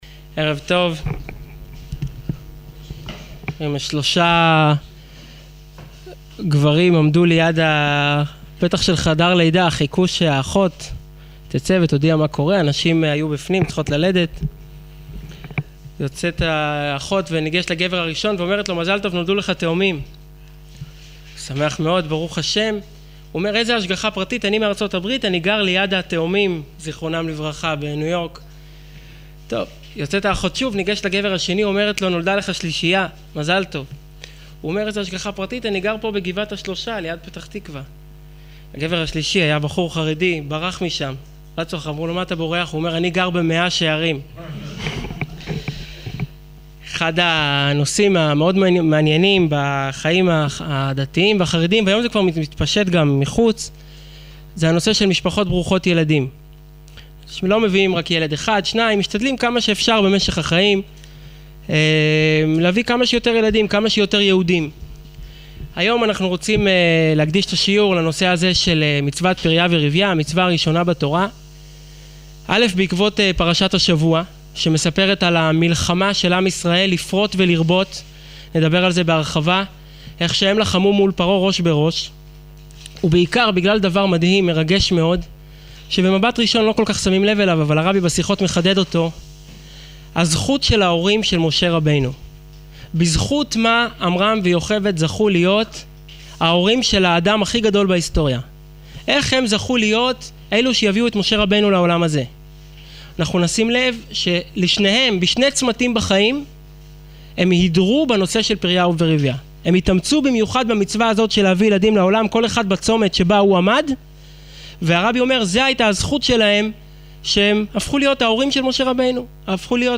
● שיעור וידאו